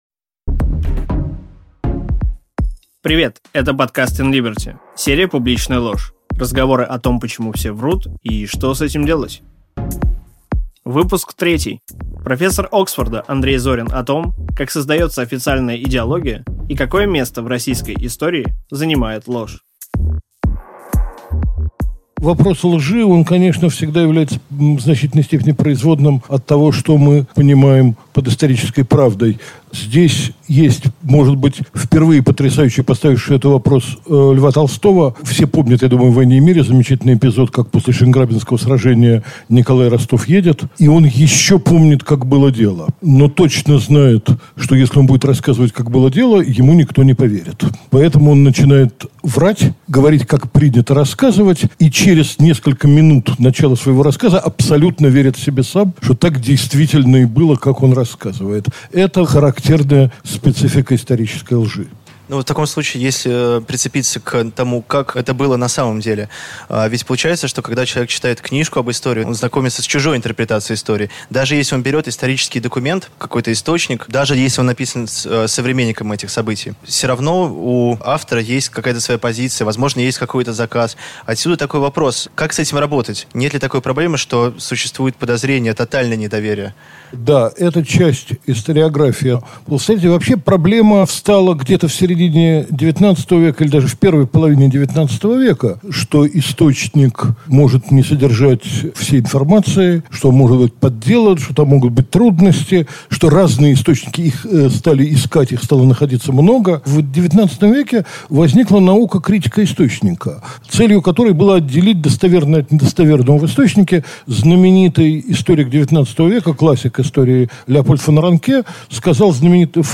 Аудиокнига Изобретение традиции | Библиотека аудиокниг